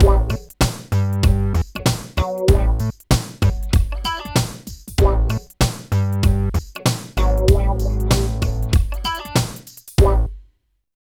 28 LOOP   -L.wav